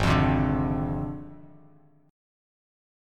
G#6b5 chord